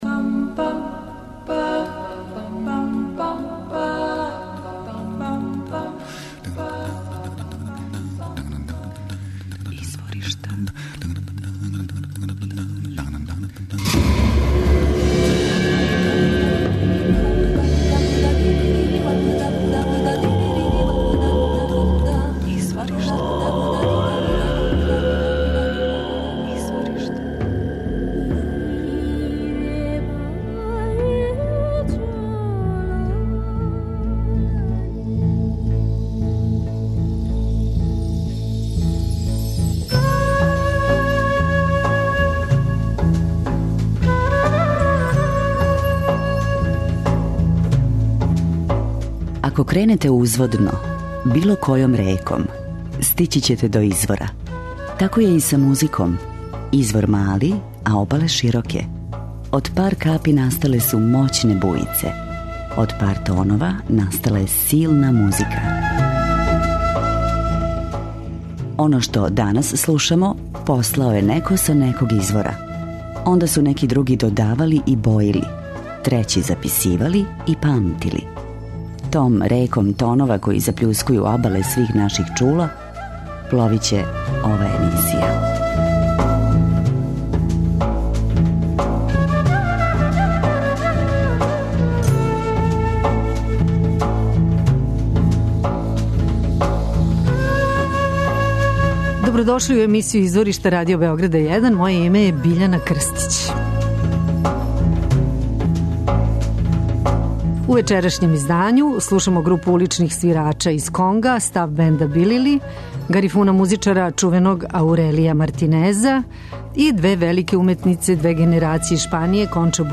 Сви чланови састава су изворни улични свираци, притом и инвалиди.
Поред класичних инструмената, бенд користи и инструмент који је осмислио један од млађих чланова састава, а који је направљен од празне конзерве, парчета дрвета и једне гитарске жице!
преузми : 28.66 MB Изворишта Autor: Музичка редакција Првог програма Радио Београда Музика удаљених крајева планете, модерна извођења традиционалних мелодија и песама, културна баштина најмузикалнијих народа света, врели ритмови...